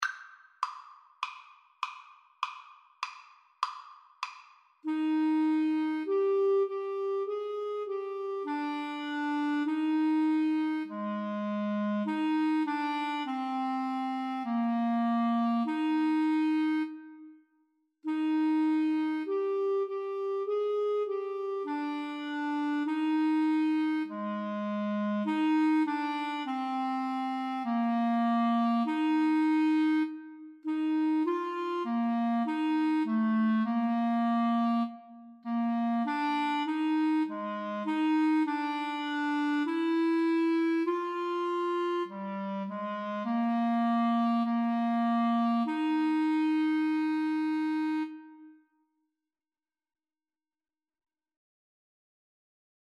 Clarinet 1Clarinet 2
4/4 (View more 4/4 Music)
Classical (View more Classical Clarinet Duet Music)